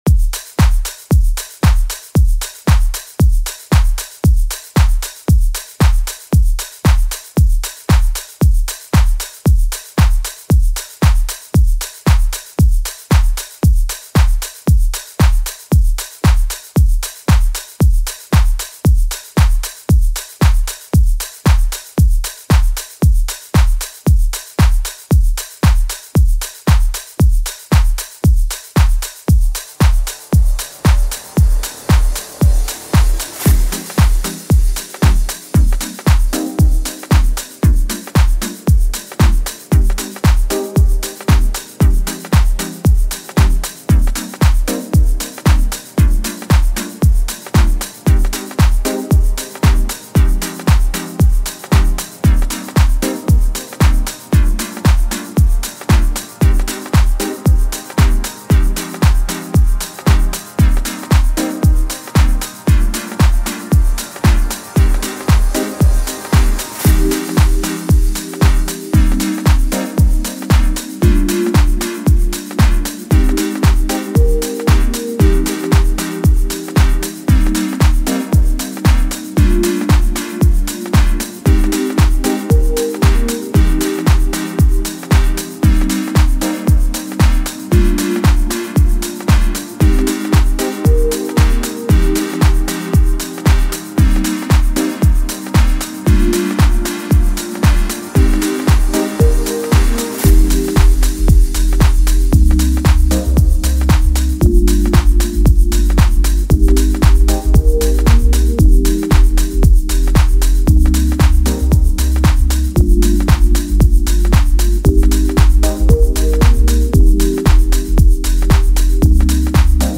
dynamic blend of vocals and production